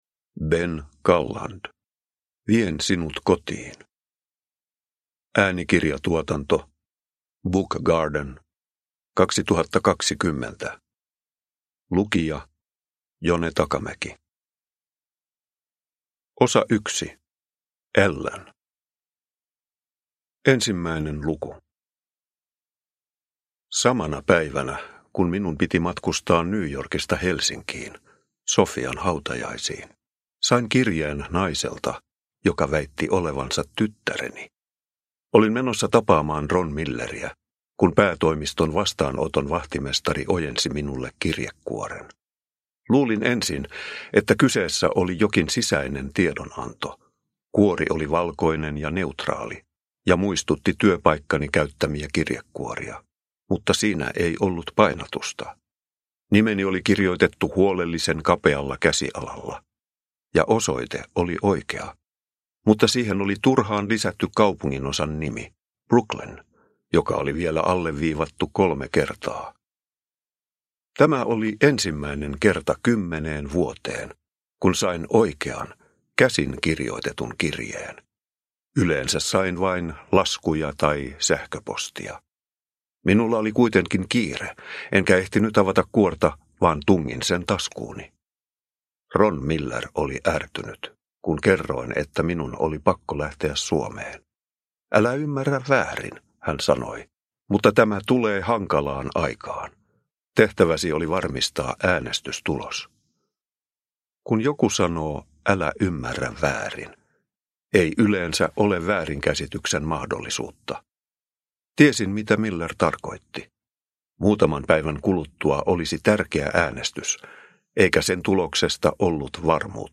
Vien sinut kotiin – Ljudbok – Laddas ner